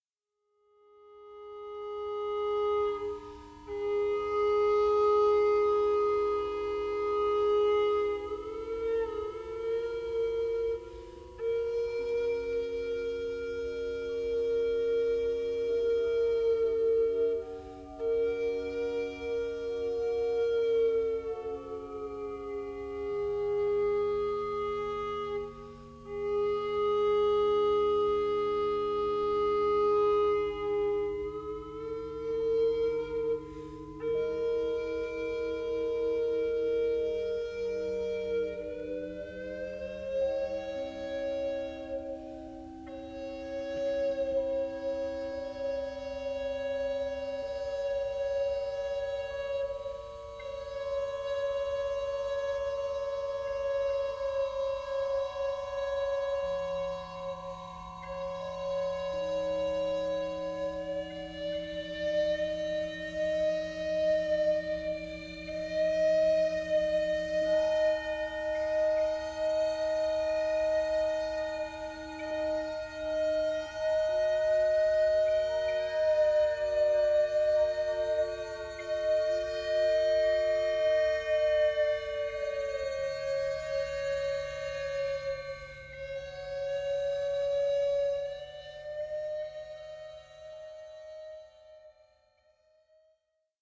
for renaissance tenor recorder and electronics
This piece is played using a real-time program on Max/MSP.